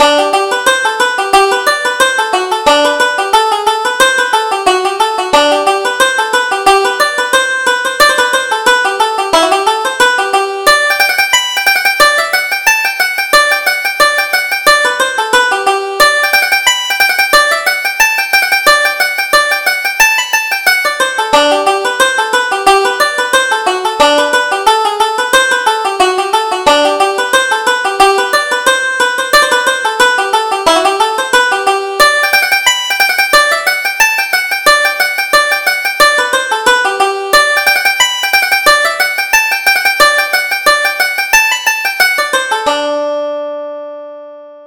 Reel: The Fourpenny Bit